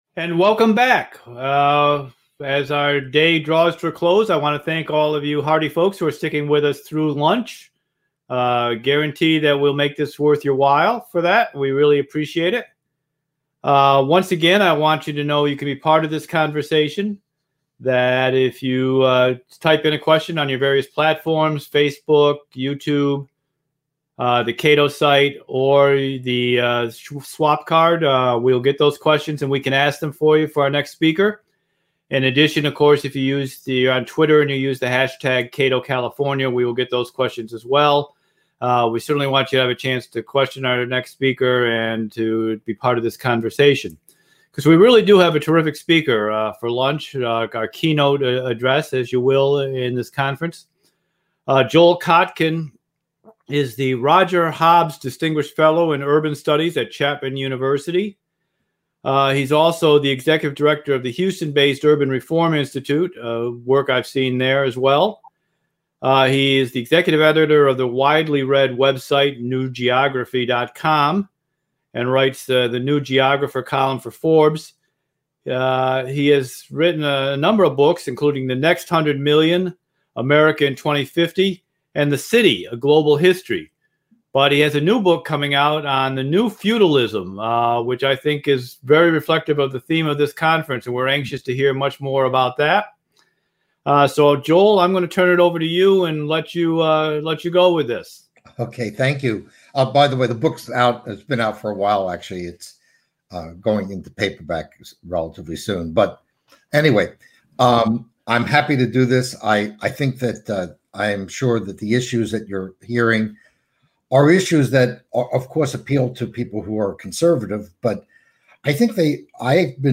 After COVID-19: Building an Inclusive Economy for California - Keynote Address and Closing Speaker